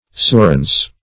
surance - definition of surance - synonyms, pronunciation, spelling from Free Dictionary Search Result for " surance" : The Collaborative International Dictionary of English v.0.48: Surance \Sur"ance\, n. Assurance.